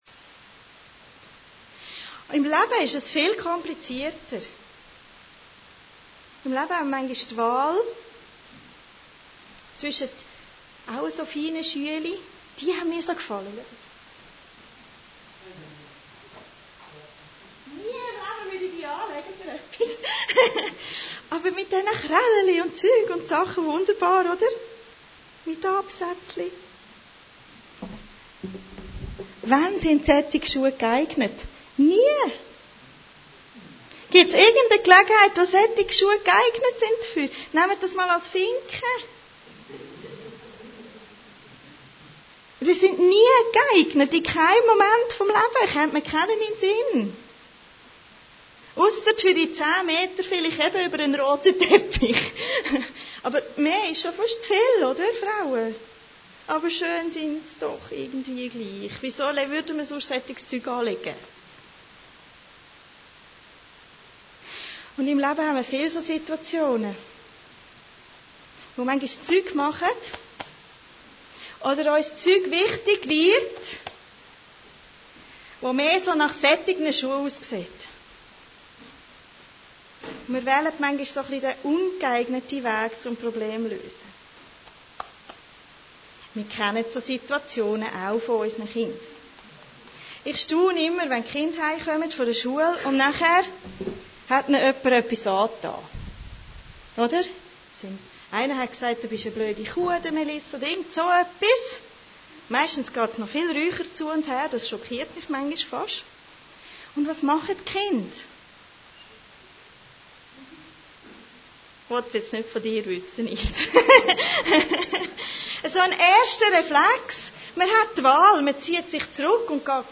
Allgemeine Predigten Date